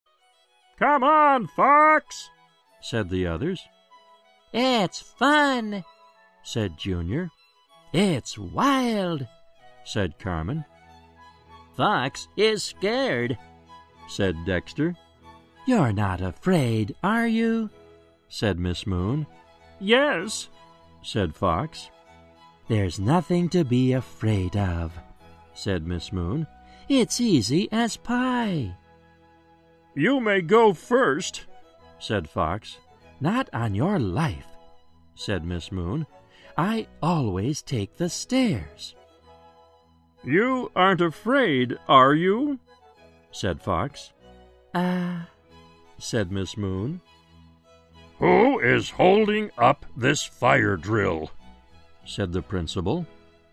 在线英语听力室小狐外传 第36期:月亮老师的听力文件下载,《小狐外传》是双语有声读物下面的子栏目，非常适合英语学习爱好者进行细心品读。故事内容讲述了一个小男生在学校、家庭里的各种角色转换以及生活中的趣事。